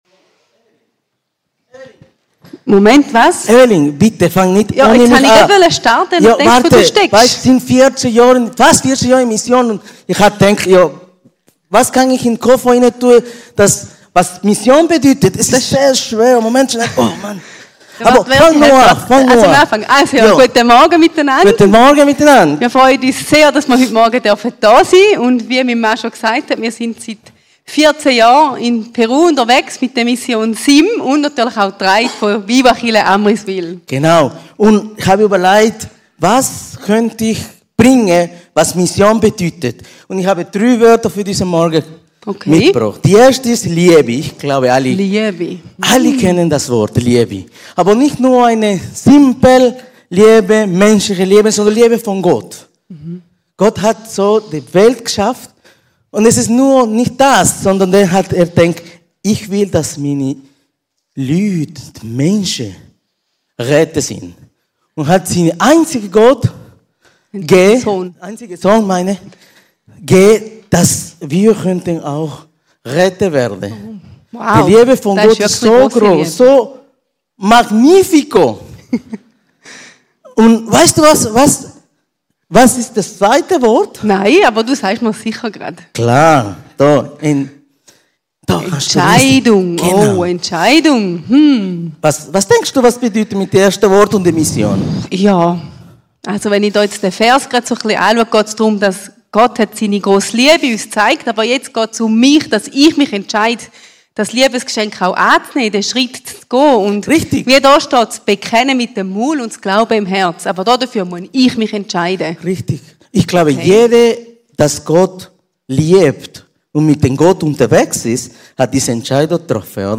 Predigten
Die neueste Predigt